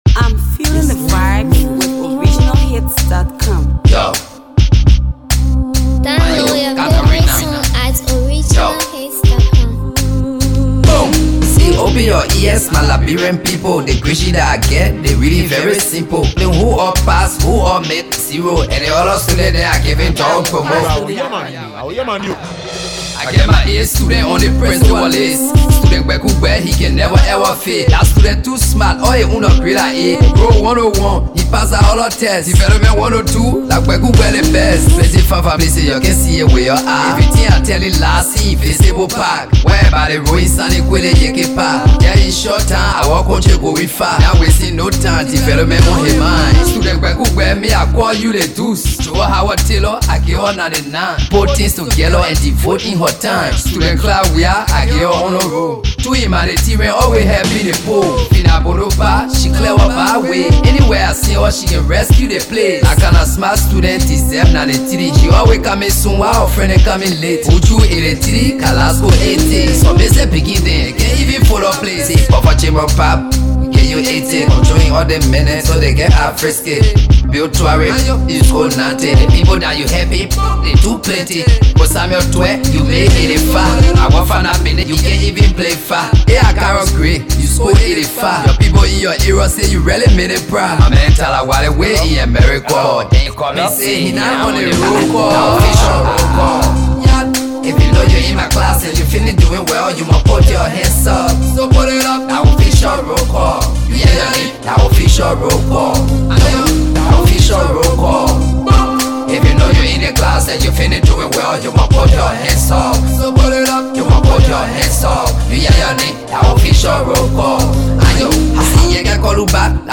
AfroAfro PopHipcoMusicreviews